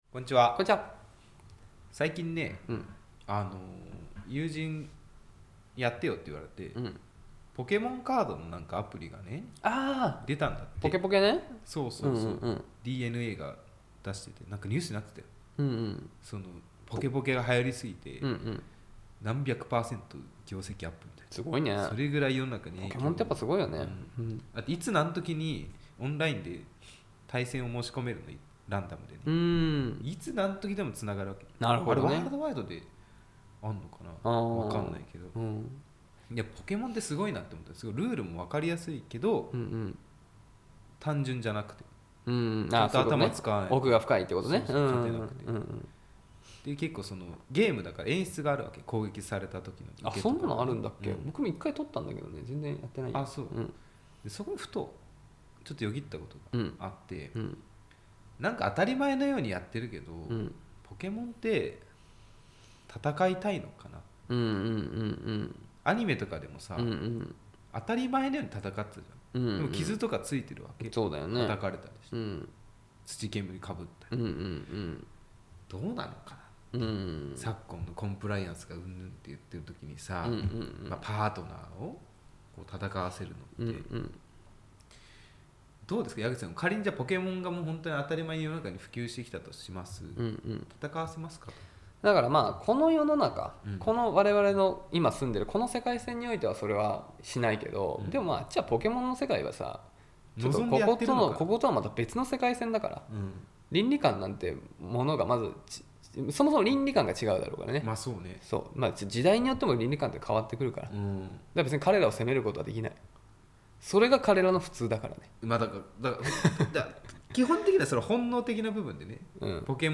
恋の街 中野よりアラサー男が恋愛トークをお届けします！